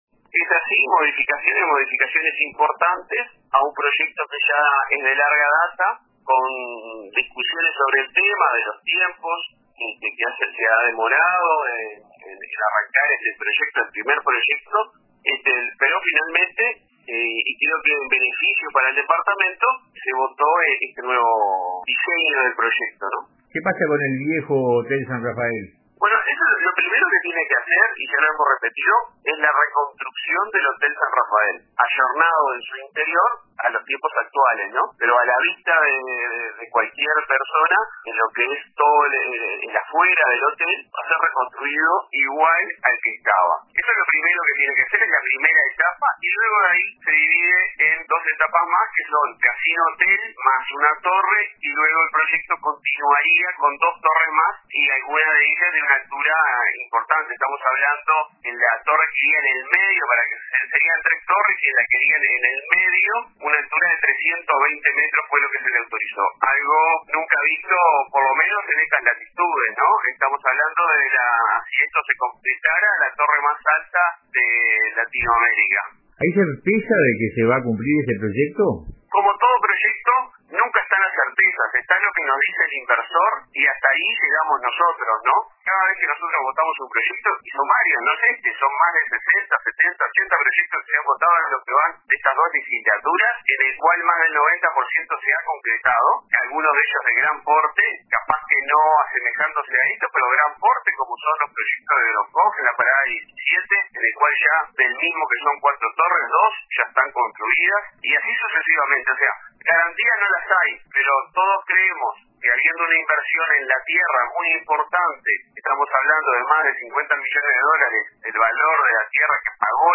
Pese a la polémica, el permiso fue aprobado y si se concreta, Punta del Este tendrá la torre más alta de Latinoamérica, dijo a RADIO RBC, el edil Luis Artola, del Partido Nacional.